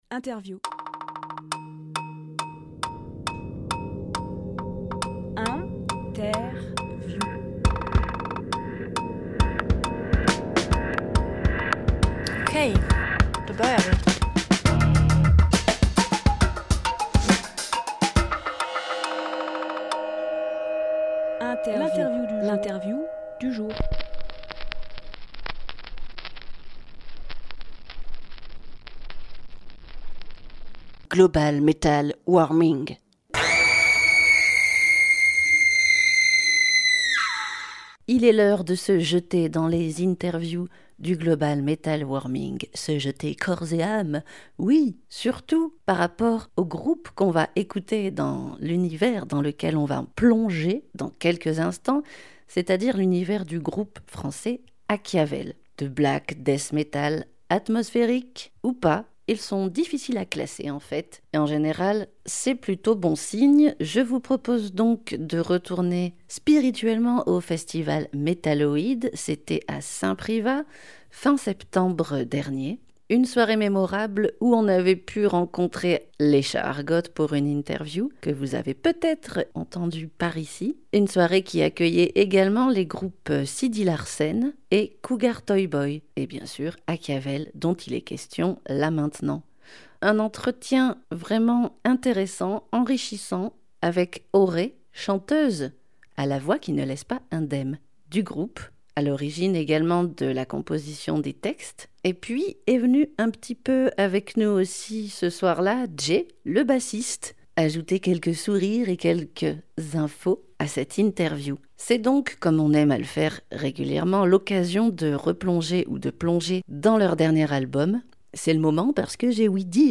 Emission - Interview